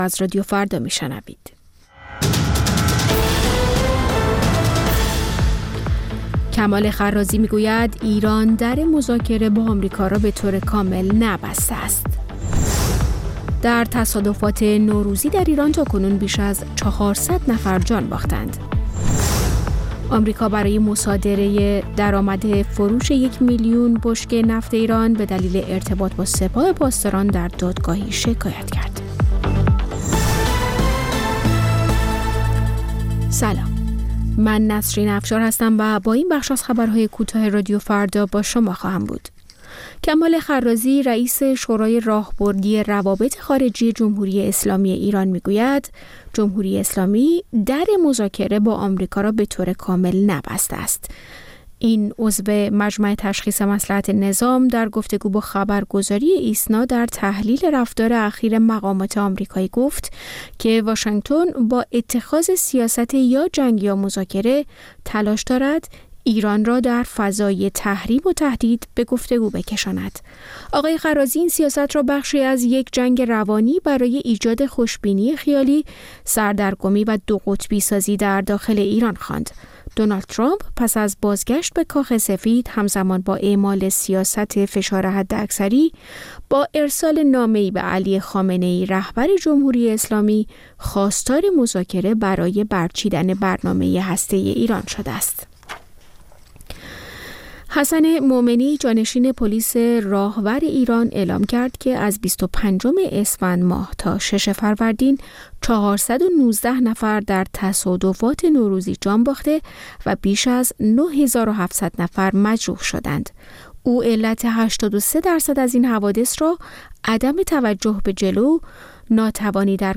سرخط خبرها ۷:۰۰